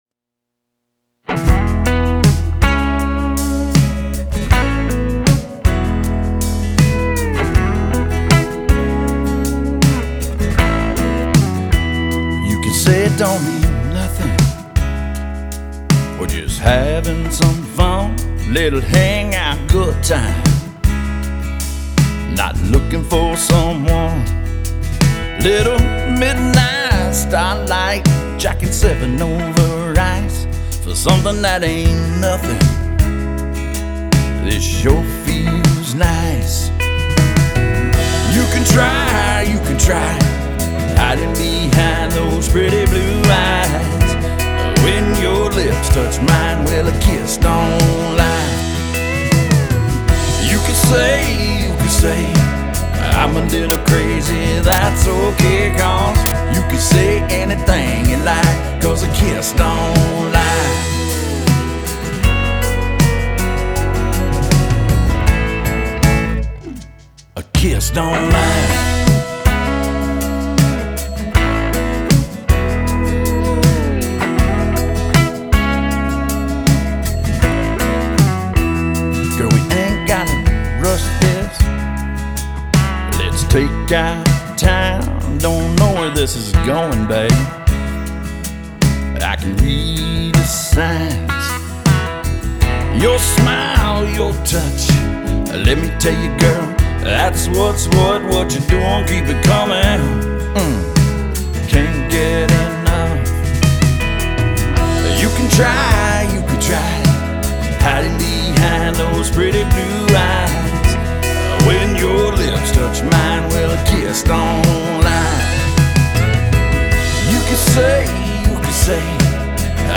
Country Singer/Songwriter
A dance-ready groove carries the track
pedal steel
punchy yet smooth vocal performance keeps the energy high
SONG GENRE – Country/Texas Country